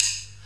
D2 SDRIM07-L.wav